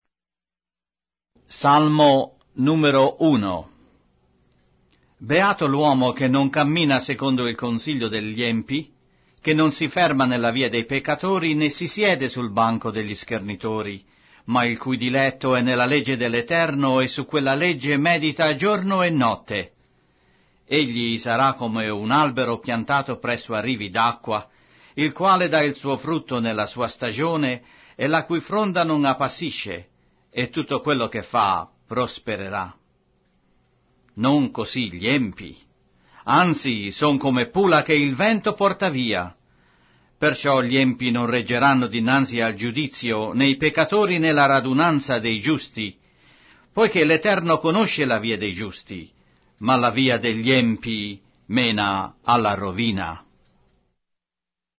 Sacra Bibbia - Riveduta - con narrazione audio - Psalms, chapter 1